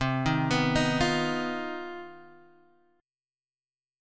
CmM11 chord